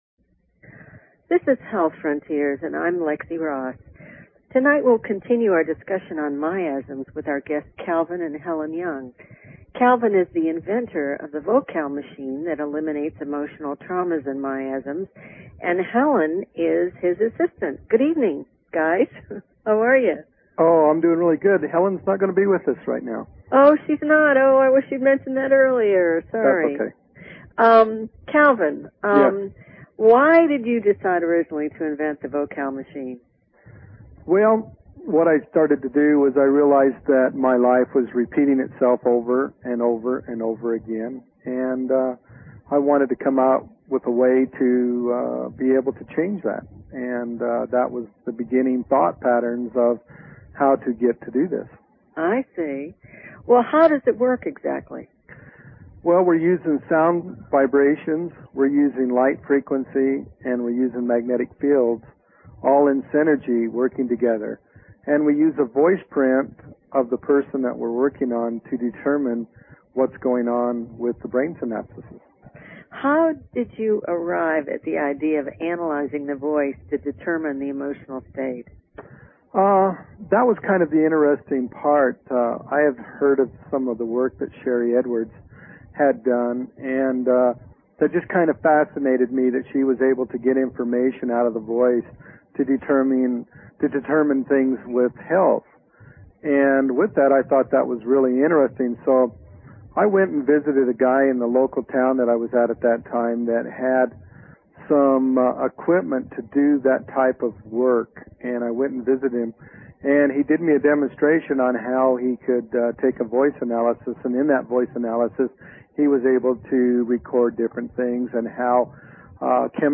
Talk Show Episode, Audio Podcast, Health_Frontiers and Courtesy of BBS Radio on , show guests , about , categorized as